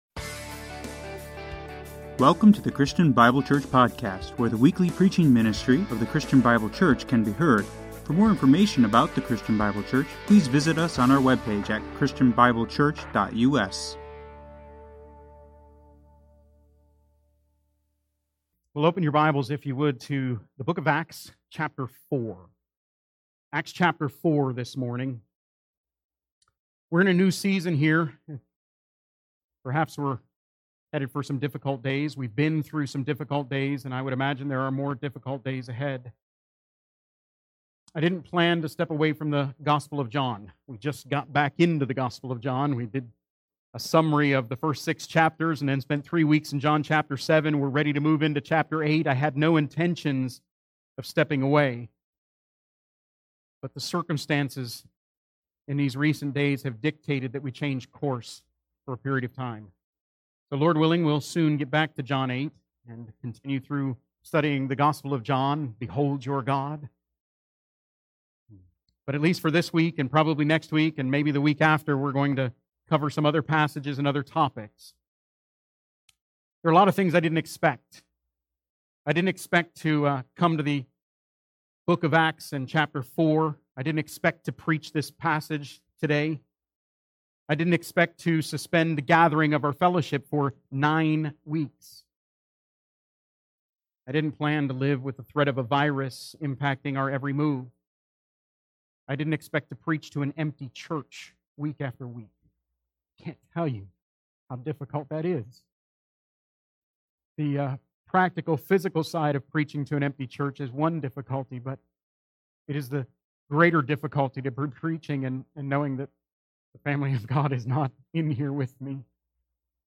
I did not expect to preach to an empty church week after week – I can’t tell you how difficult that is.